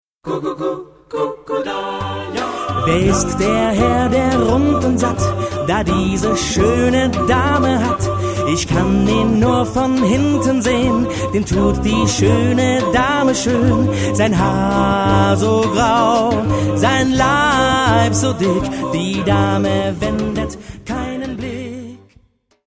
Genre-Stil-Form: weltlich ; Choraljazz
Charakter des Stückes: erzählend ; jazzy ; spöttisch
Chorgattung: SATB  (4 gemischter Chor Stimmen )
Tonart(en): G-Dur